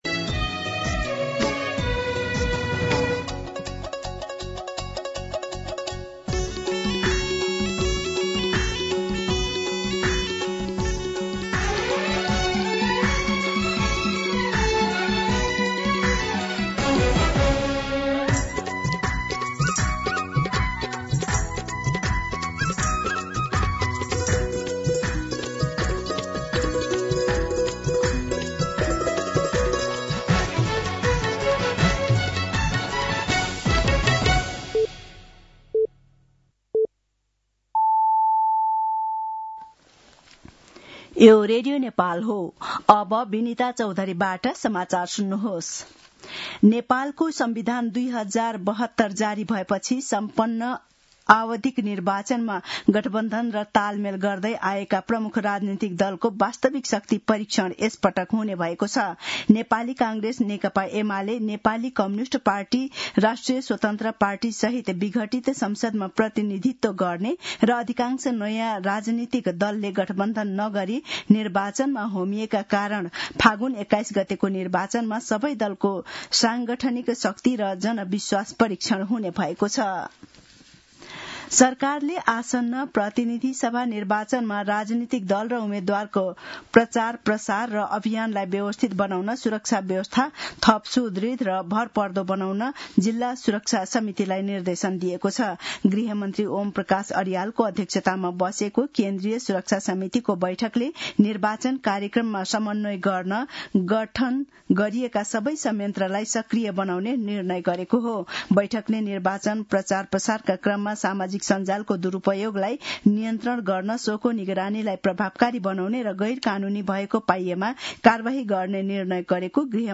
दिउँसो १ बजेको नेपाली समाचार : ८ माघ , २०८२